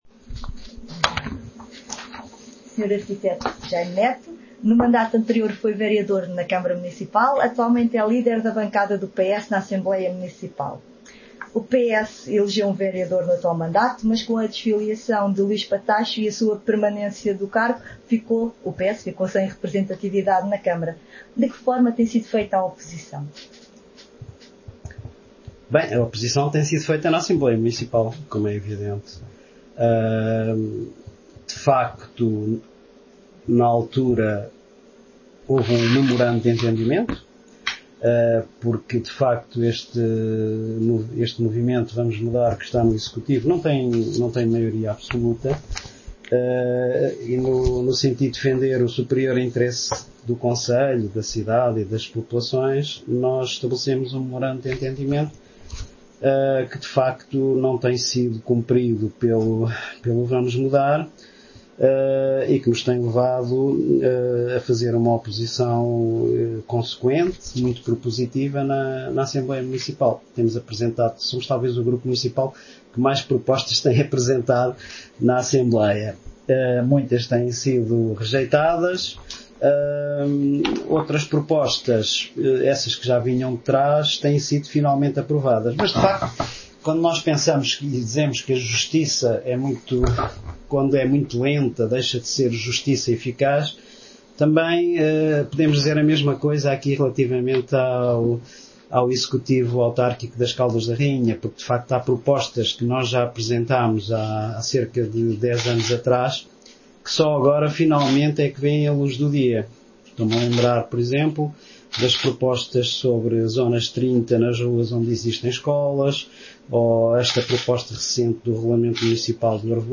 Gazeta das Caldas entrevista Jaime Neto, líder da bancada do PS na Assembleia Municipal
Entrevista-Jaime-Neto_PS.mp3